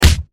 Punch4.wav